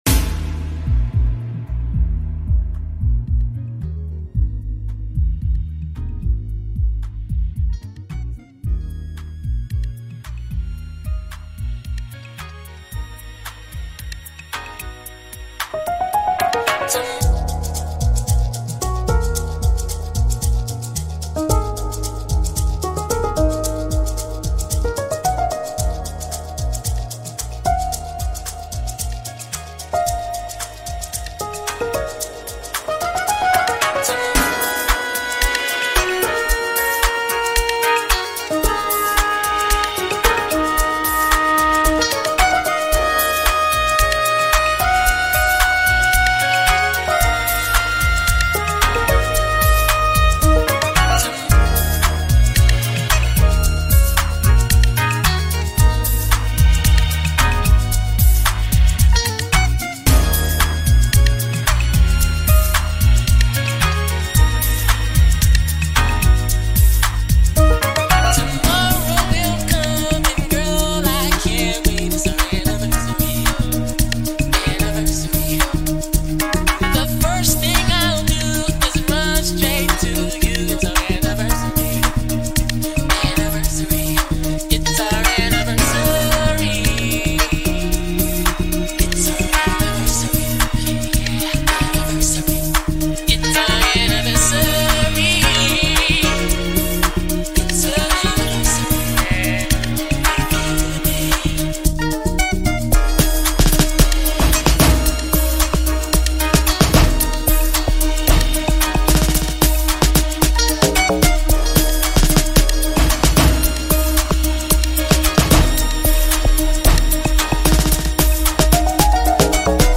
piano rendition
the soulful and emotional side